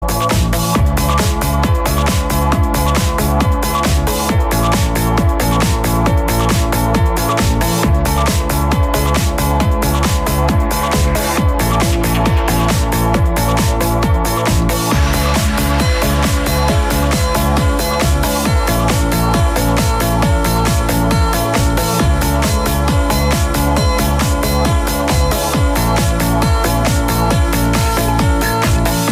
subtle guitar riff